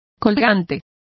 Also find out how colgante is pronounced correctly.